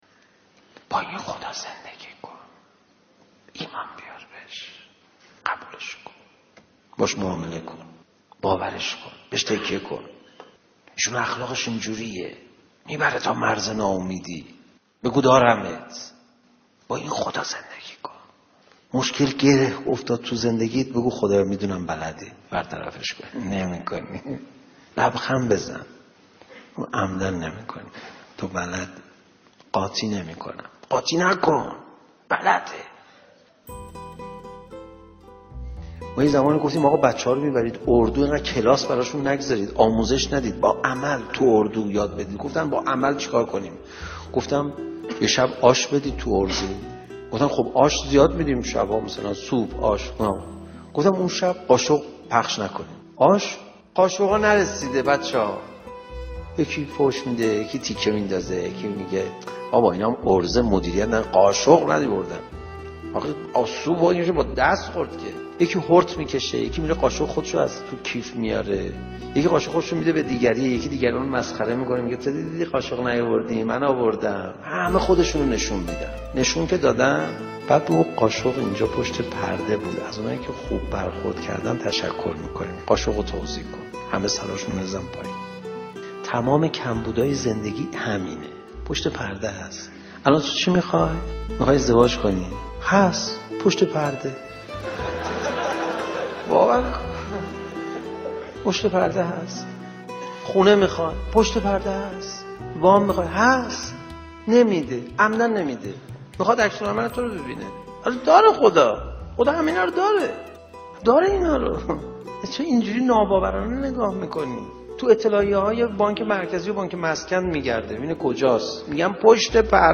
*¤•❤ با خدا زندگی کن ❤•¤* کلیپ صوتی زیبا از سخنان آقای پناهیان
سخنرانی